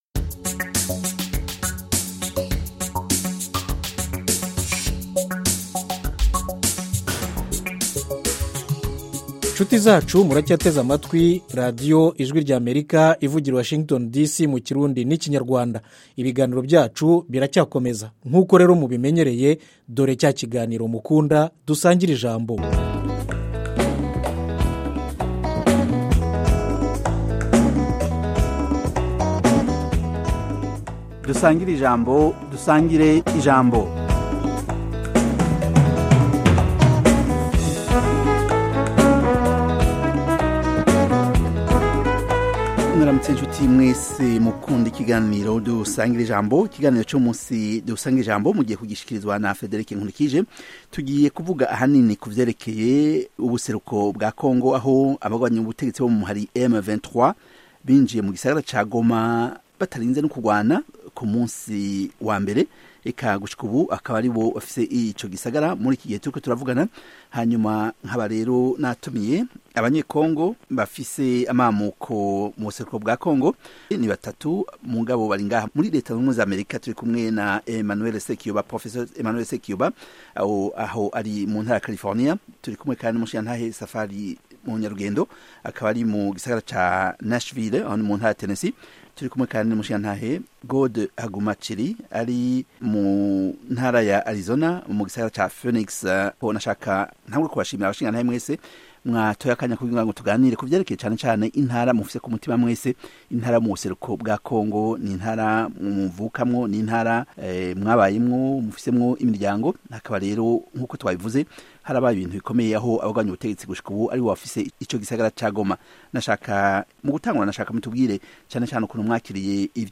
Panel Discussion